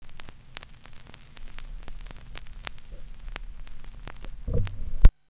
VINYLSTOP -R.wav